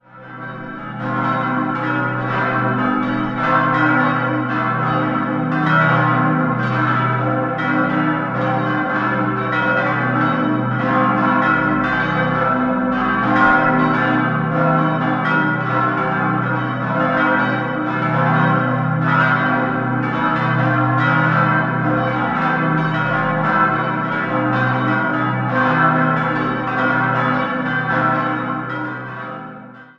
Im Inneren findet der Besucher eine reichhaltige, originale Ausstattung vor. 6-stimmiges Geläute: h°-dis'-fis'-gis'-ais'-cis'' Alle Glocken wurden im Jahr 1949 von Karl Czudnochowsky in Erding gegossen.